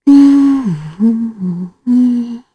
Aselica-Vox_Hum_kr.wav